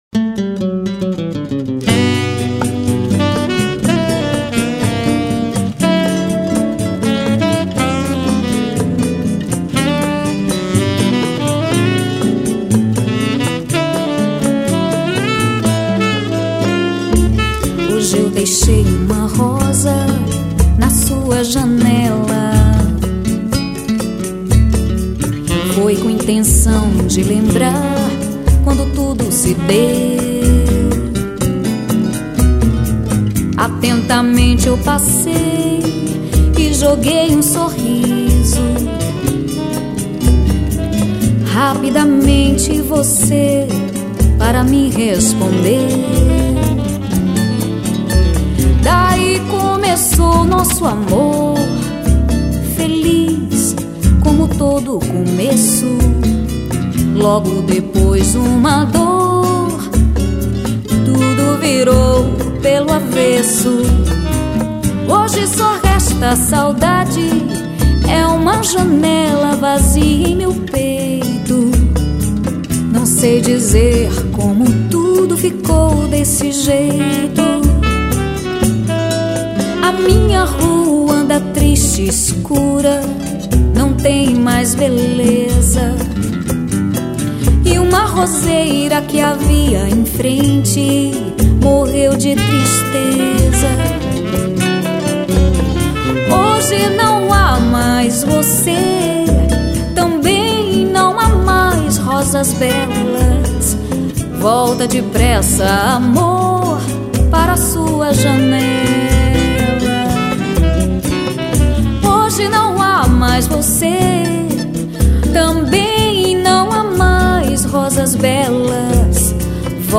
2798   03:51:00   Faixa: 7    Samba